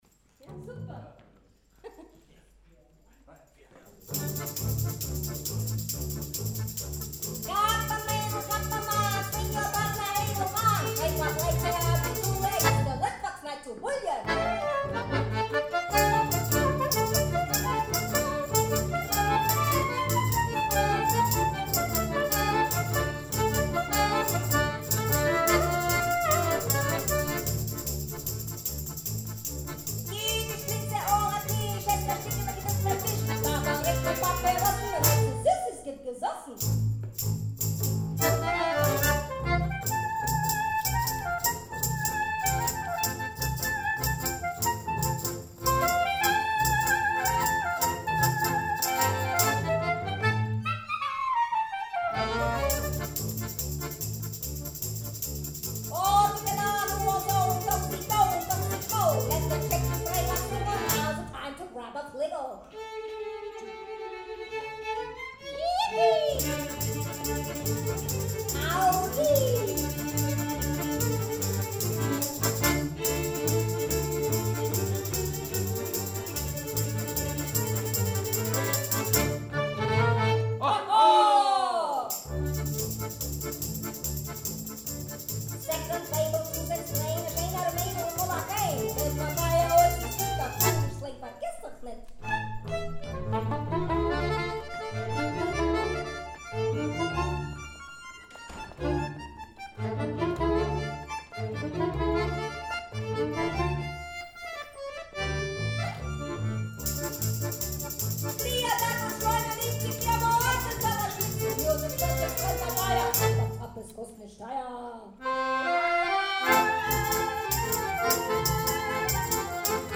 KLezmermusik und jiddische Lieder